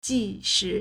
即使 jíshǐ
ji2shi3.mp3